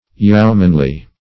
Search Result for " yeomanly" : The Collaborative International Dictionary of English v.0.48: Yeomanly \Yeo"man*ly\, a. Pertaining to a yeoman; becoming or suitable to, a yeoman; yeomanlike.